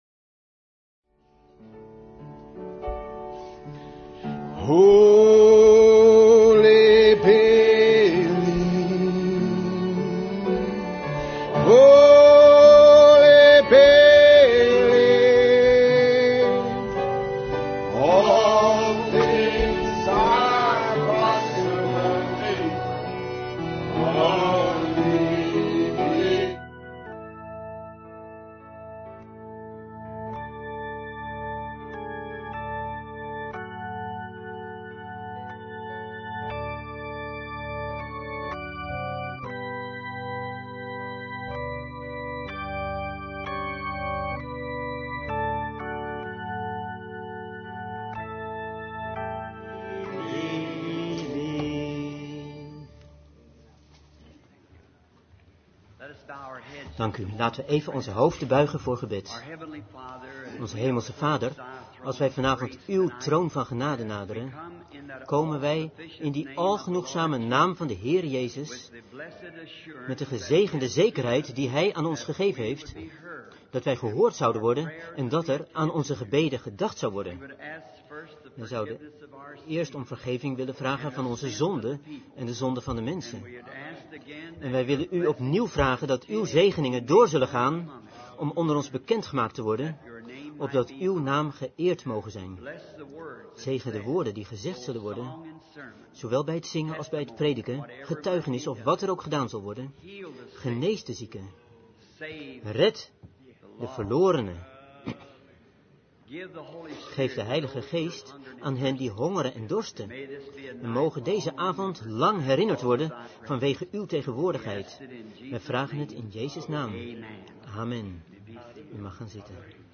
Vertaalde prediking "Why?" door William Marrion Branham te Great Western Exhibit Center, South Gate, California, USA, 's avonds op vrijdag 22 juni 1962